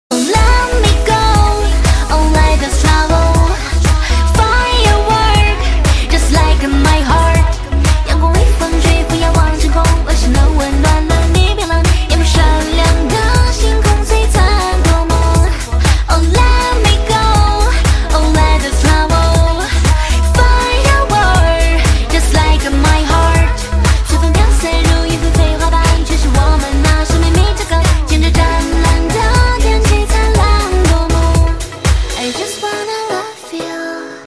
M4R铃声, MP3铃声, 华语歌曲 65 首发日期：2018-05-14 22:00 星期一